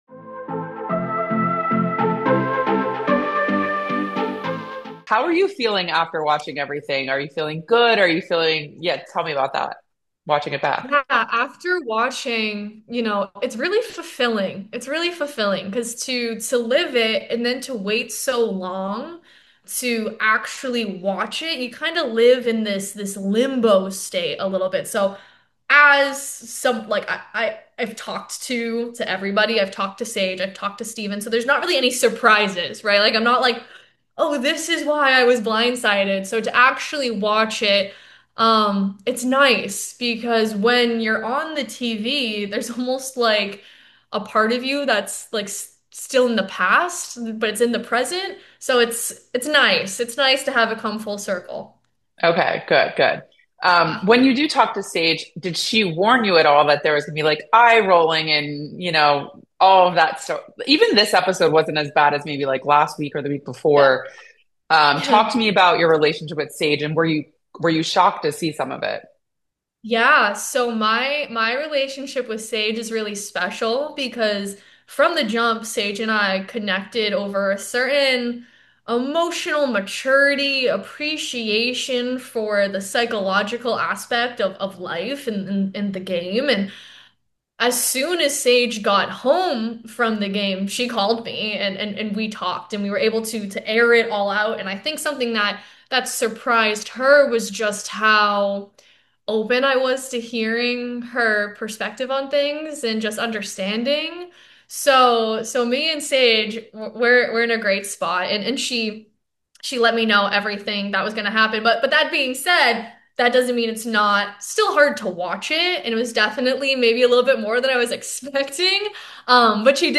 Survivor 49 Exit Interview